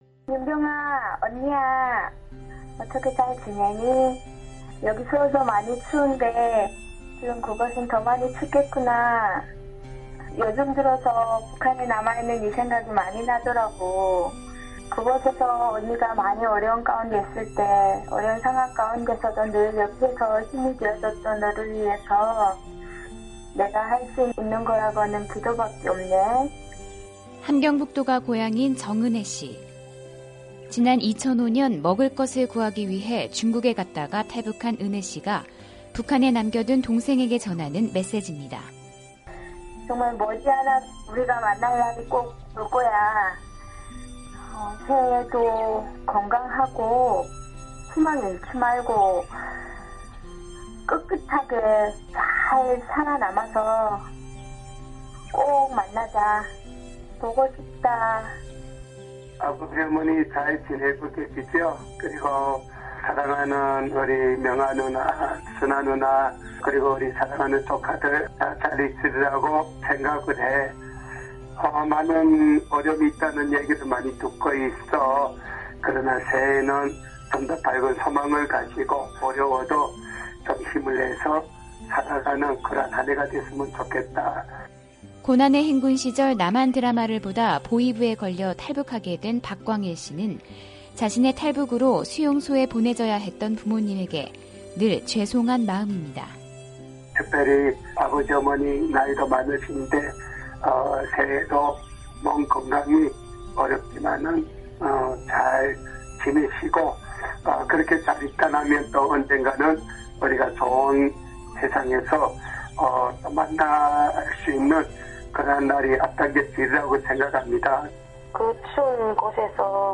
[특파원 리포트] 한국 내 탈북자들 북한 가족에 보내는 새해 메시지